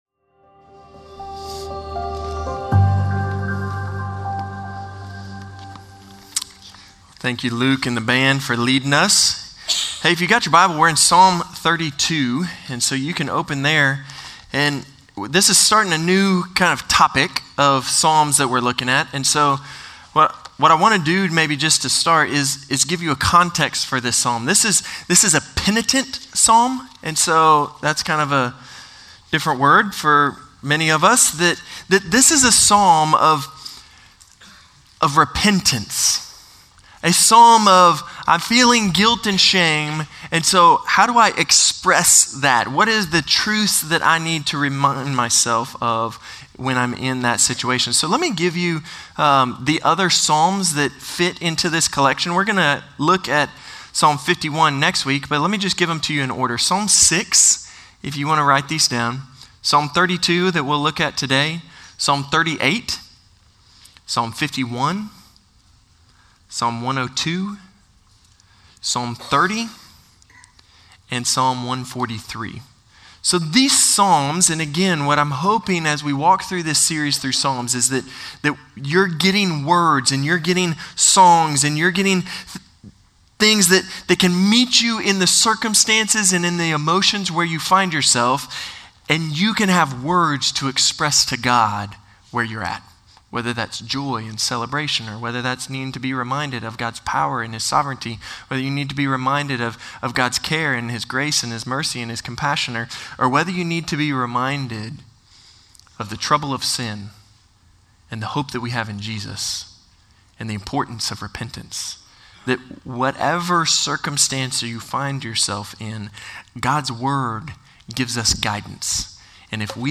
Norris Ferry Sermons Nov. 24, 2024 -- The Book of Psalms -- Psalm 32 Nov 24 2024 | 00:36:09 1x 00:00 / 00:36:09 Subscribe Share Spotify RSS Feed Share Link Embed